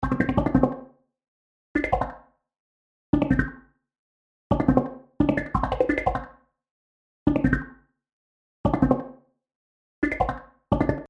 描述：用Reason 4制作的泡沫状相位合成器循环，适合节奏感强的旋律。